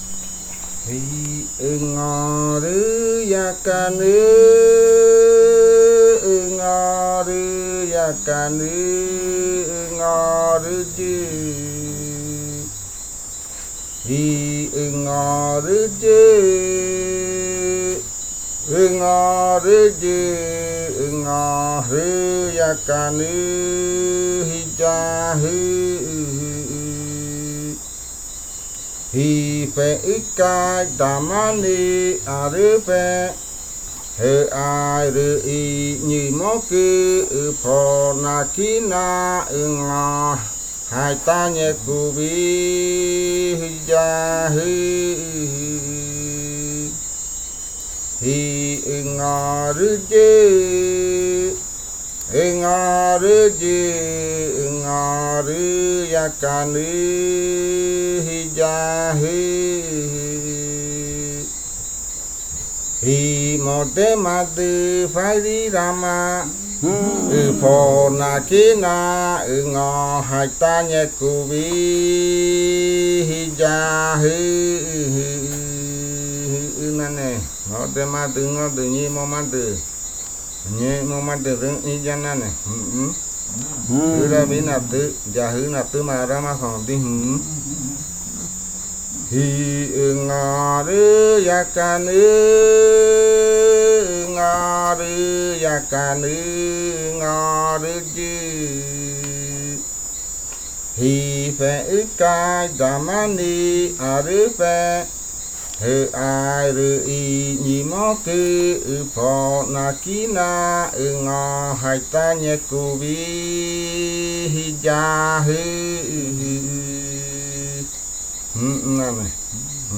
Leticia, Amazonas, (Colombia)
Grupo de danza Kaɨ Komuiya Uai
Canto fakariya de la variante Jimokɨ (cantos de guerrero).
Fakariya chant of the Jimokɨ variant (Warrior chants).